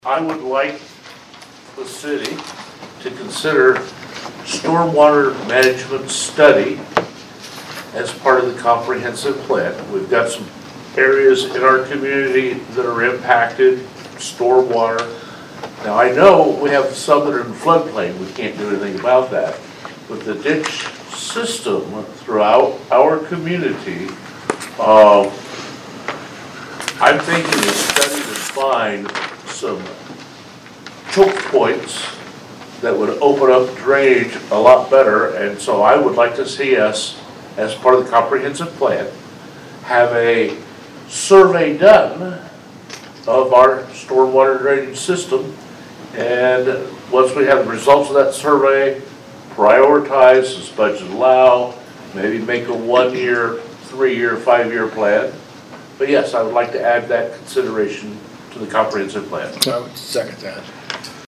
Speaking during last week’s Vandalia City Council meeting, Alderman Bret Brosman stated he thought it would be a good idea to add the study to the plan.